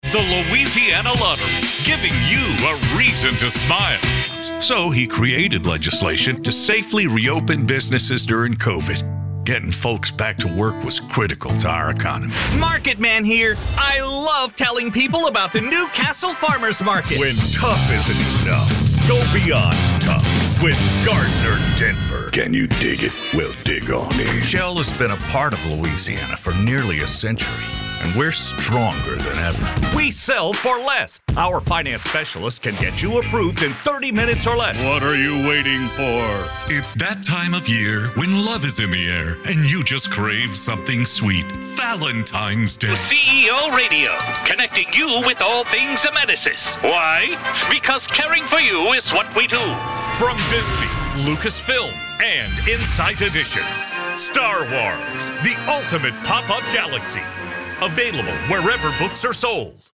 Male
English (North American)
Adult (30-50)
Heavy, smooth, conversational, aggressive? Yes.
Radio Commercials